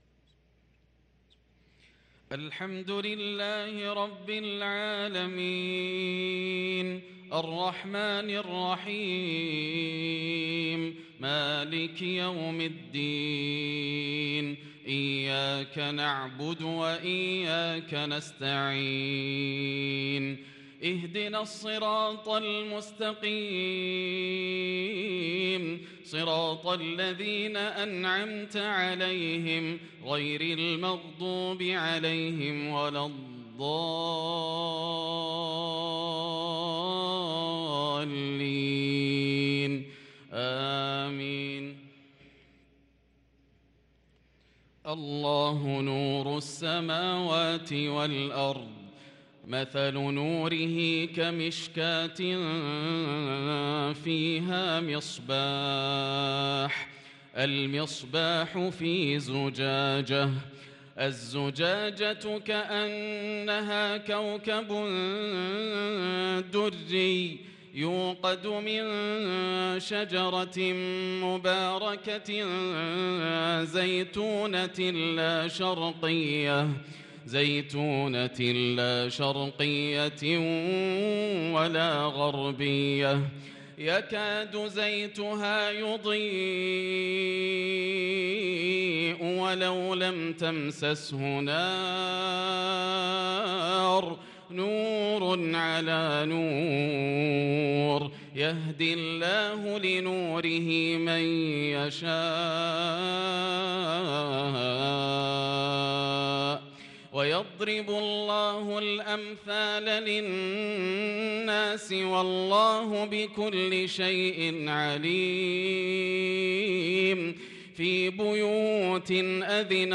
صلاة العشاء للقارئ ياسر الدوسري 13 شوال 1443 هـ